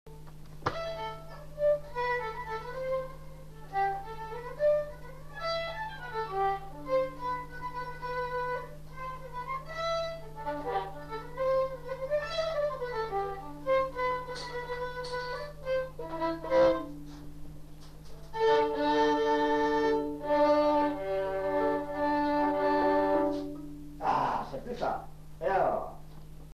Valse
Lieu : Saint-Michel-de-Castelnau
Genre : morceau instrumental
Instrument de musique : violon
Danse : valse
Notes consultables : Joue la première partie seulement.